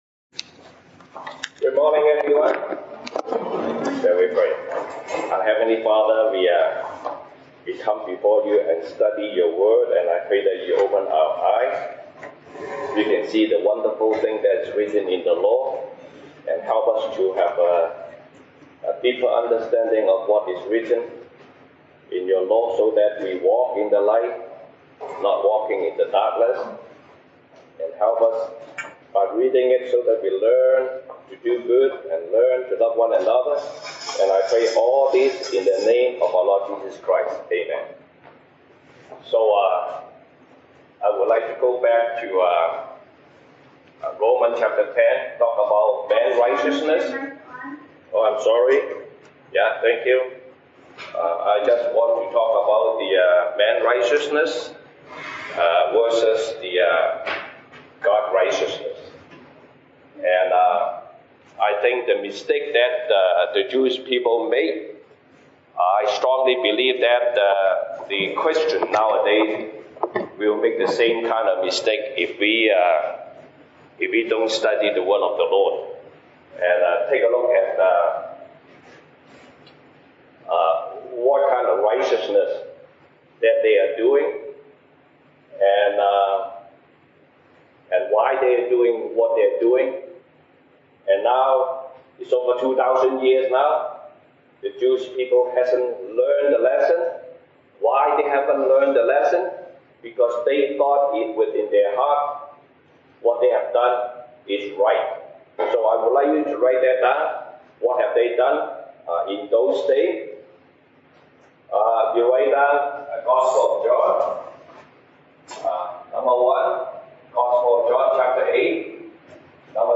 西堂證道 (英語) Sunday Service English: Man’s righteousness versus God’s righteousness
Service Type: 西堂證道 (英語) Sunday Service English Topics: Man's righteousness versus God's righteousness